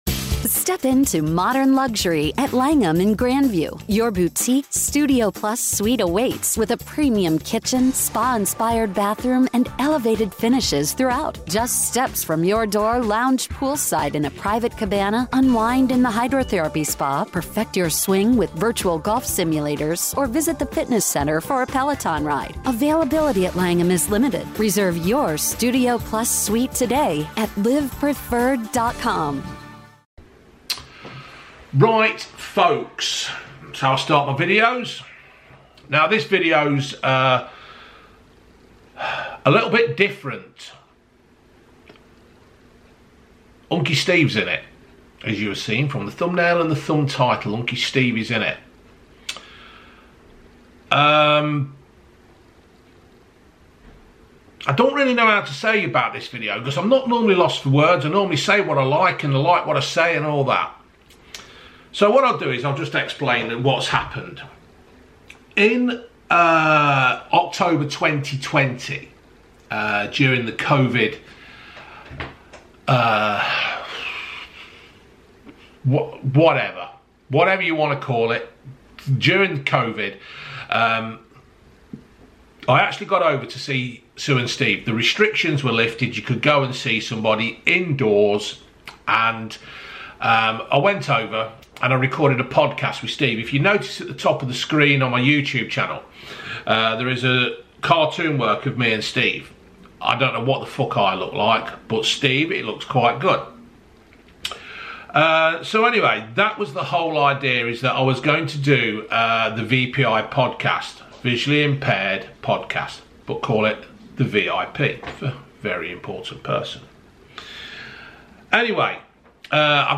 I thought this podcast was deleted and lost forever but while looking at my old camera I found it on an old memory card. So I have stuck it all together, no editing and raw.